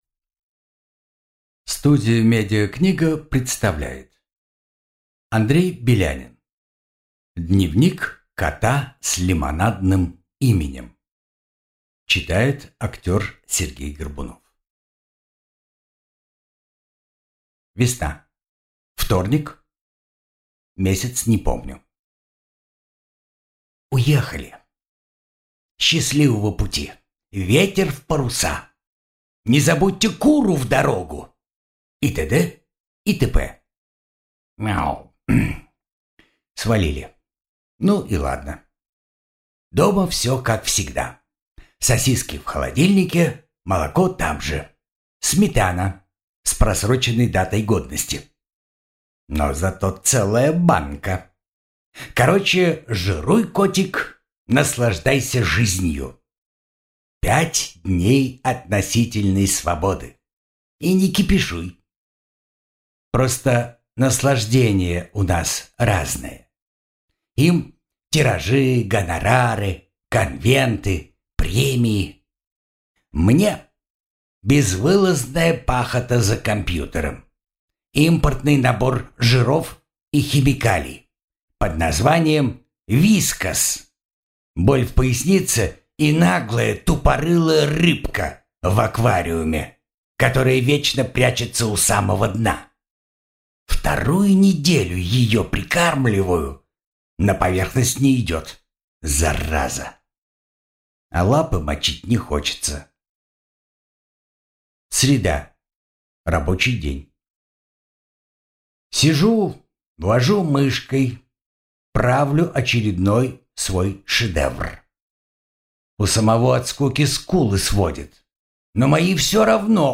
Аудиокнига Дневник кота с лимонадным именем | Библиотека аудиокниг